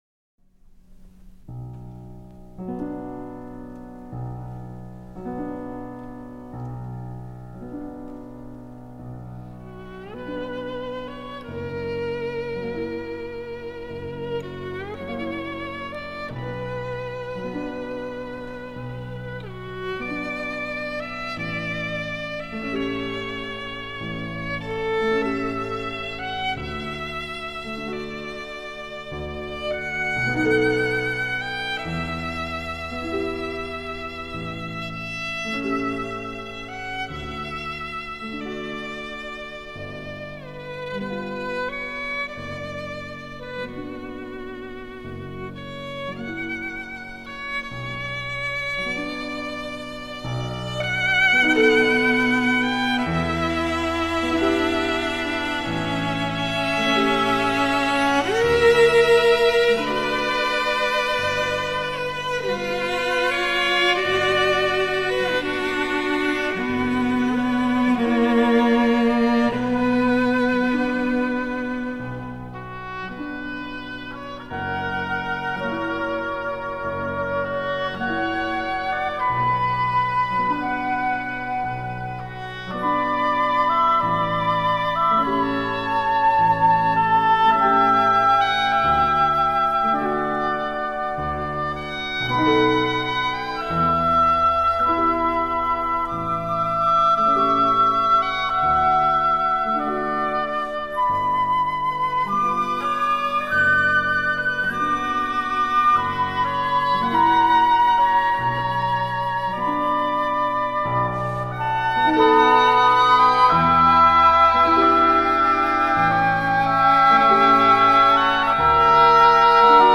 Делимся с вами записью эфира. Серебряный Дождь – Красноярск : В нашей уютной студии поселился милый плюшевый бобрёнок.
Мы узнали, как в Красноярске особенных детей ставят на горные лыжи, как ребята с дополнительными потребностями играют на сцене настоящие спектакли, а также как родители объединяются в сообщества, чтобы вместе решать насущные проблемы. Получился очень душевный разговор о важных вещах.